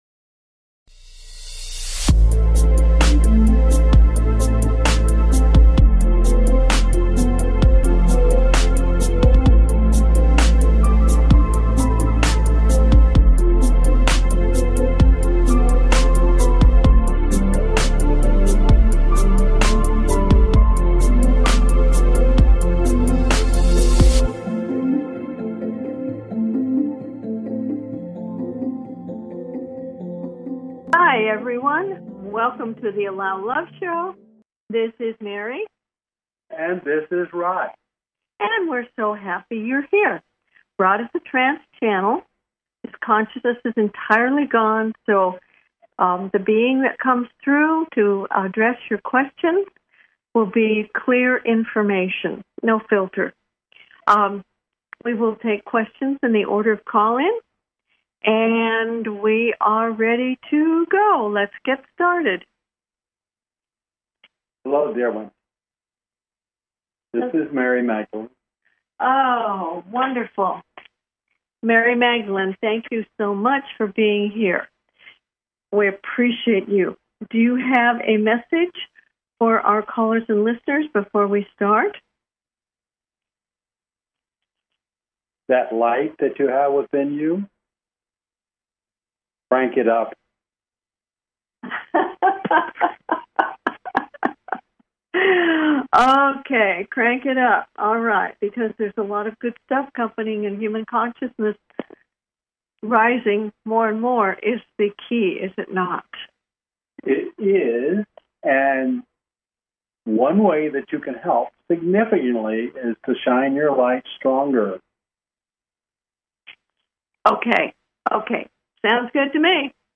Talk Show Episode, Audio Podcast
Their purpose is to provide answers to callers’ questions and to facilitate advice as callers request.